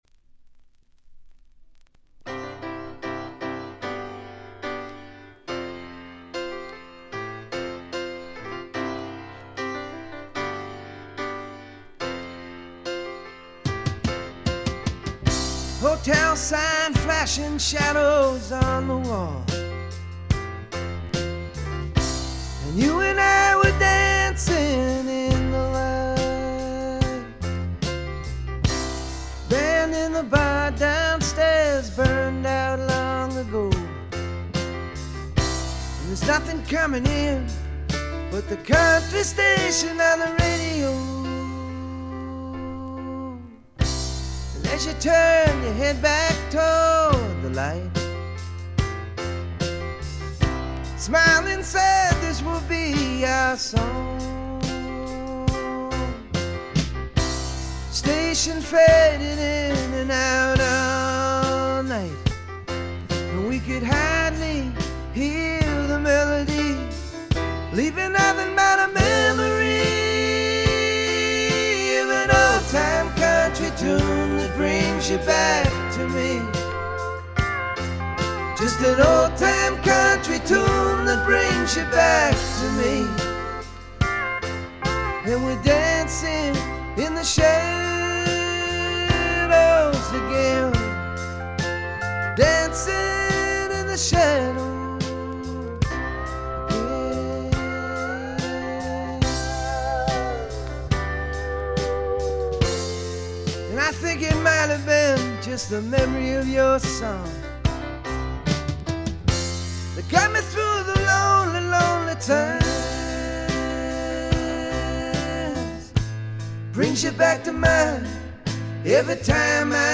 主に静音部で軽微なチリプチ（A3序盤では目立ちます）。
ボブ・ディランのフォロワー的な味わい深い渋い歌声はそのままに、むしろ純度がより上がった感があります。
試聴曲は現品からの取り込み音源です。